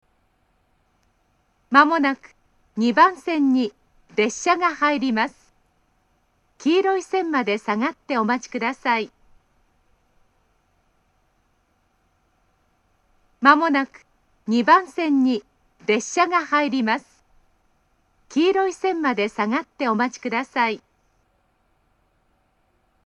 ２番線接近放送
koriyama2bansen-sekkin.mp3